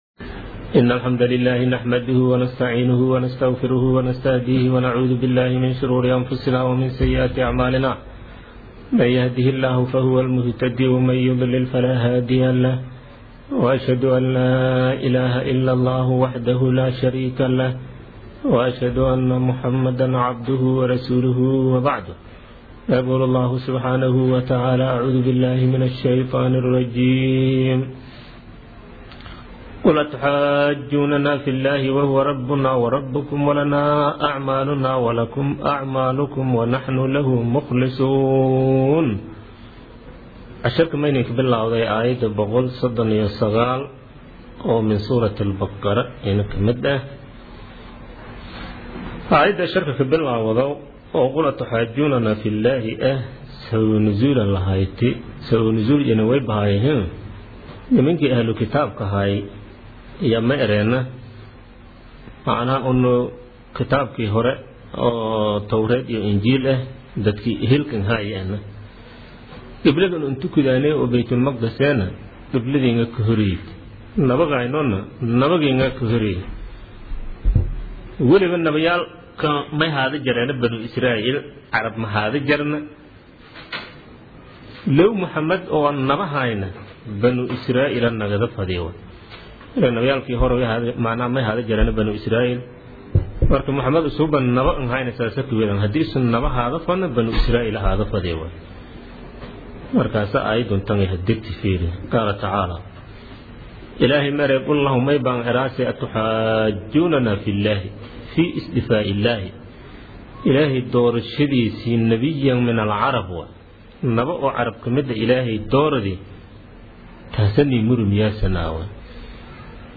Casharka Tafsiirka Maay 17aad